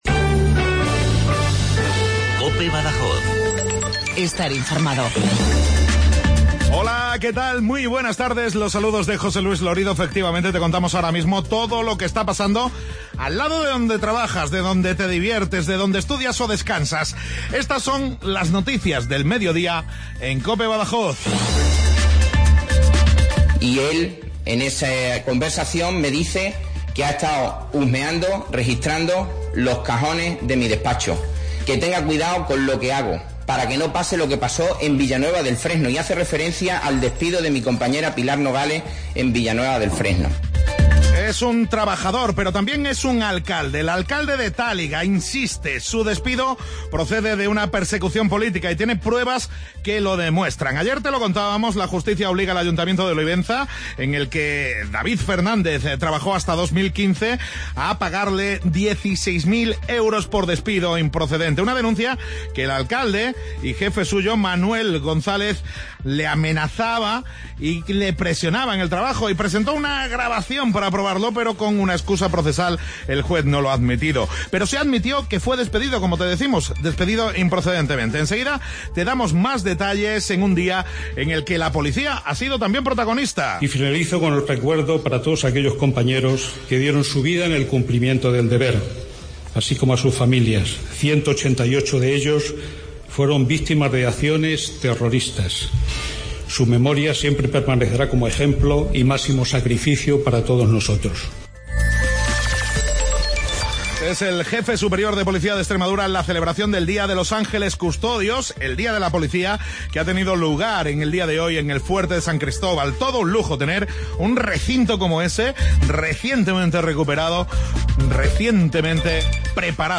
Informativo MEDIODIA Cope 051016